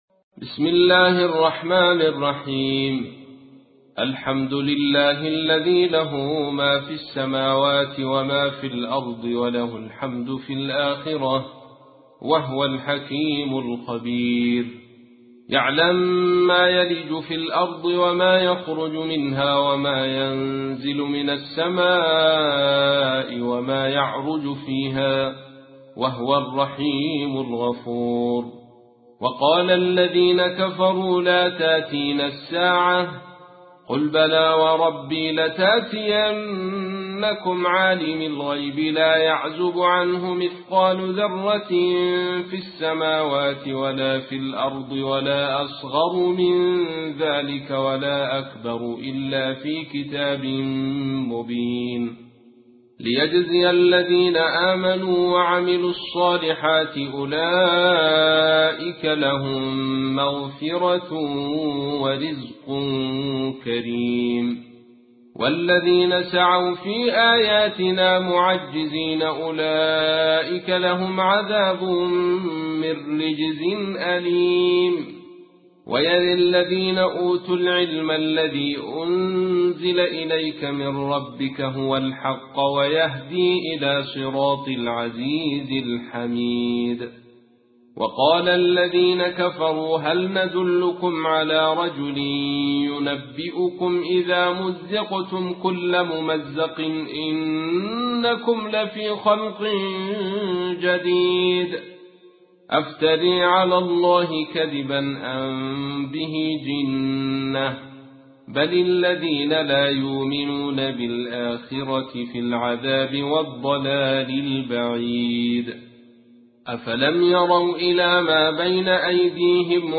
تحميل : 34. سورة سبأ / القارئ عبد الرشيد صوفي / القرآن الكريم / موقع يا حسين